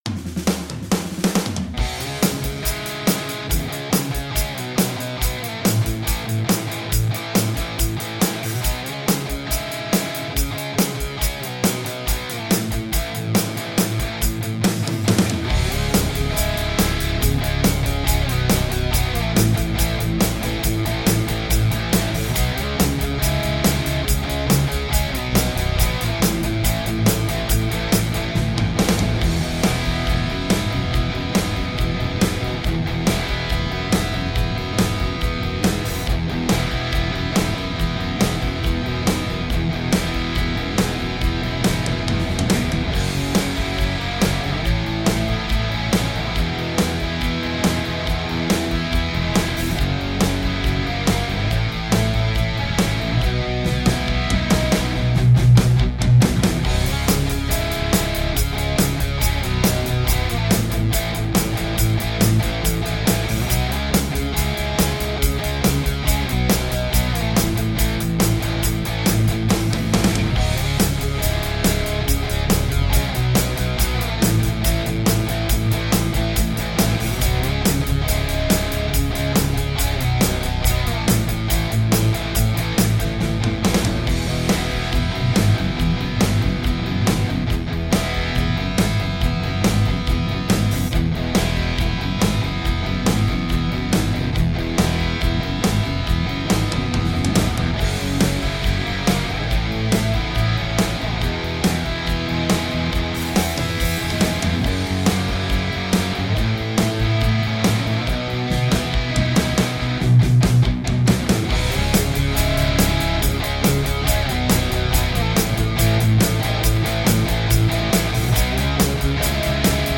Dávám sem vzorek bez BB bicích (jen pattern z B3) a celý nápad s bubnama od Buddyho.